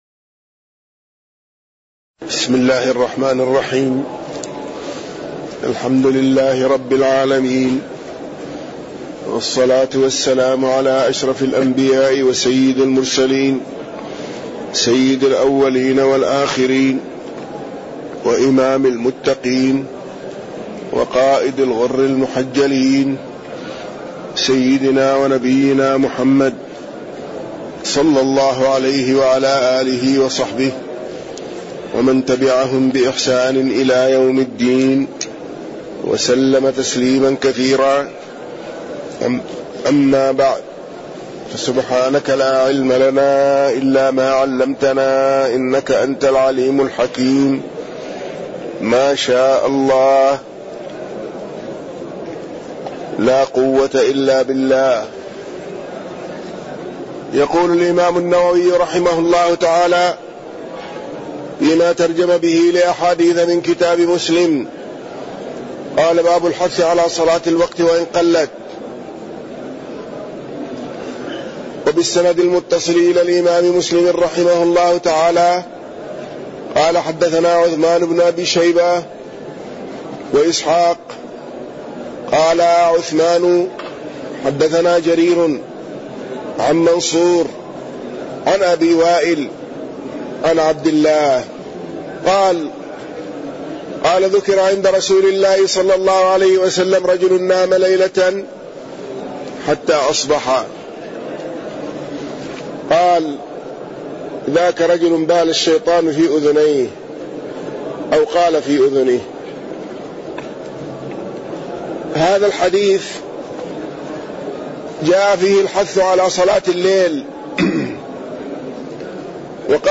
تاريخ النشر ١٢ صفر ١٤٣١ هـ المكان: المسجد النبوي الشيخ